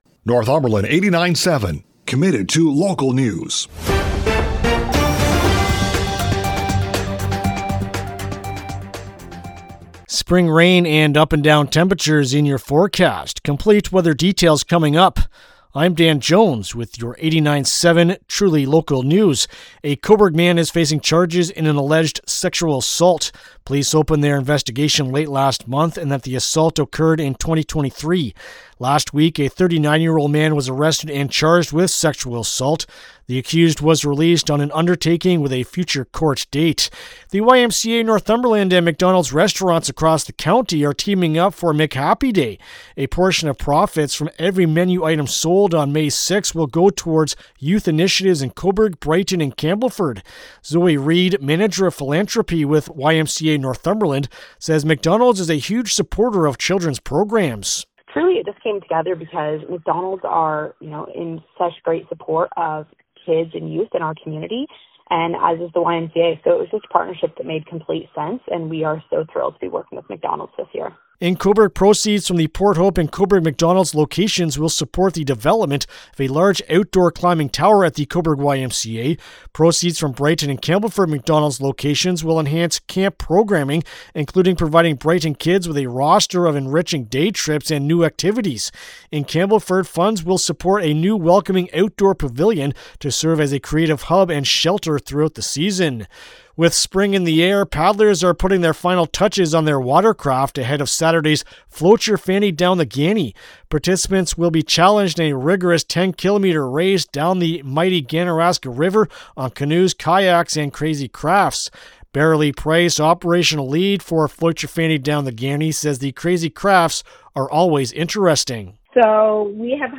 Thursday-Aprl-9-AM-News-2.mp3